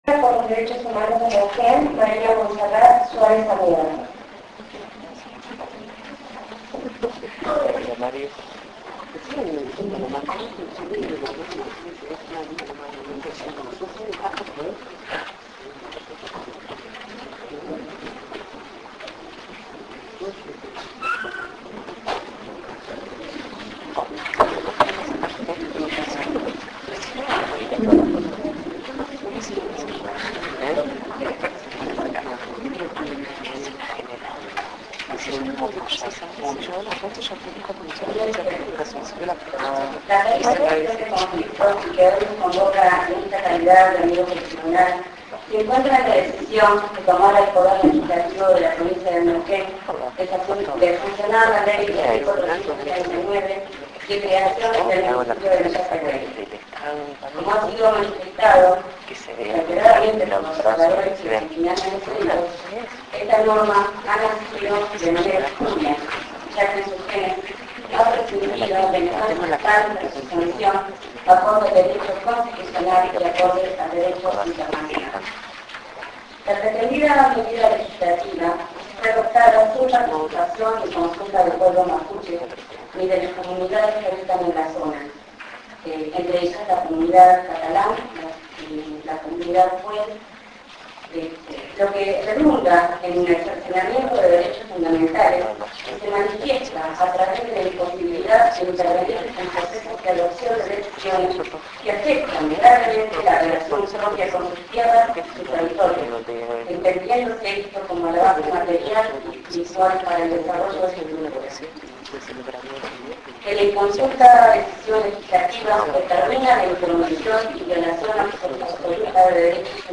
La audiencia se realizó en el Colegio de Abogados de Neuquén en medio de un importante operativo de seguridad.